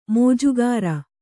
♪ mōjugāra